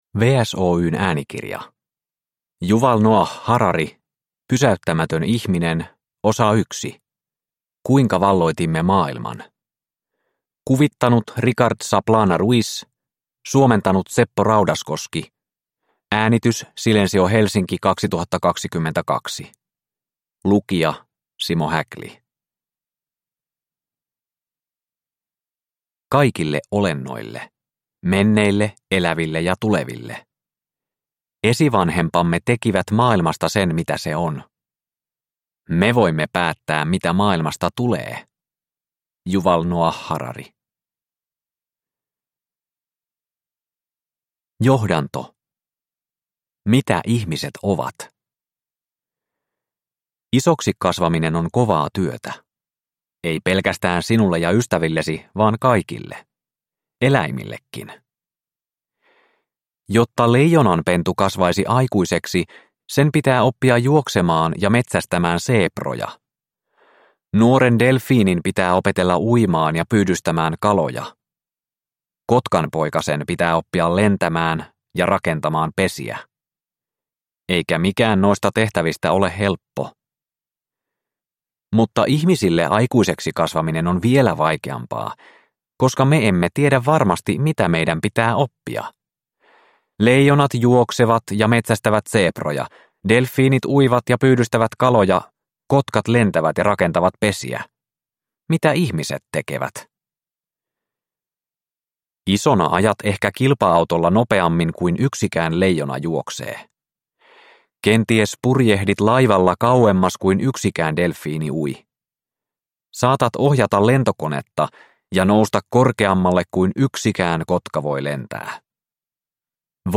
Pysäyttämätön ihminen: Kuinka valloitimme maailman – Ljudbok – Laddas ner